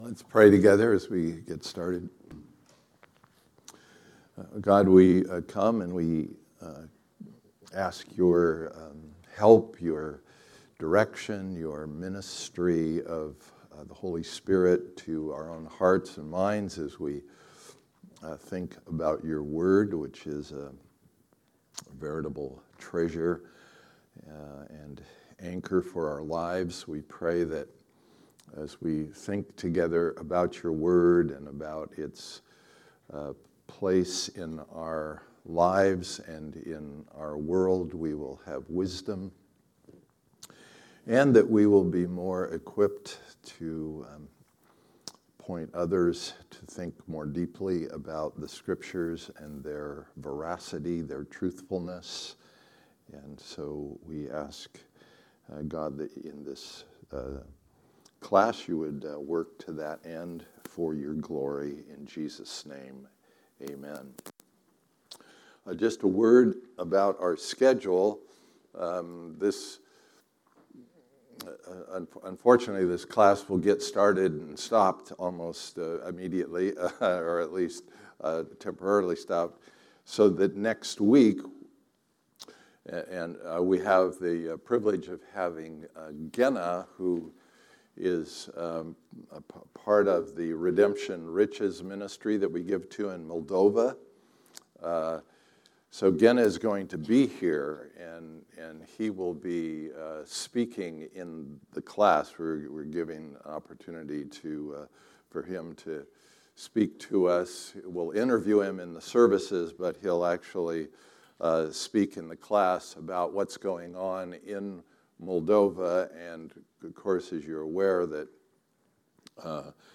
2023 Series: Trustworthy Type: Sunday School